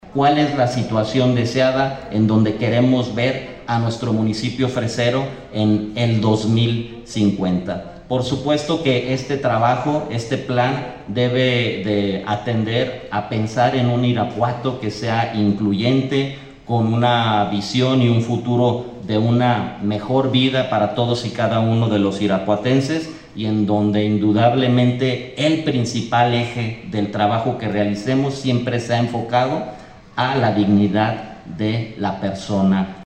AudioBoletines
Rodolfo Gómez, presidente interino